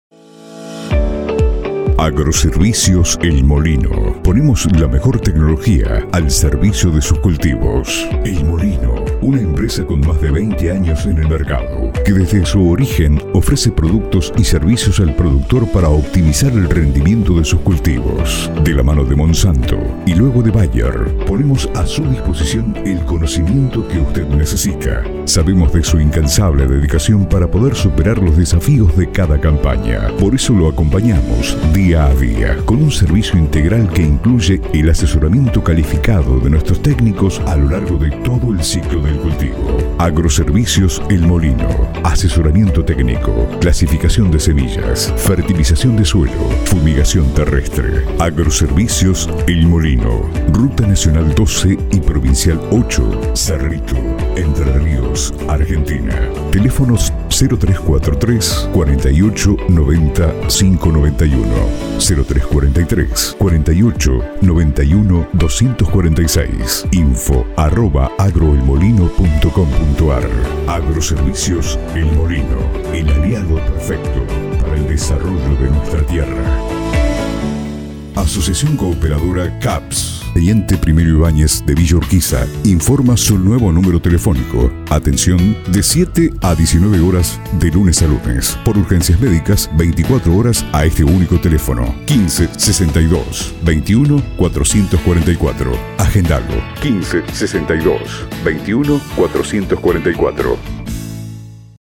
voice over
Demo-institucionales.mp3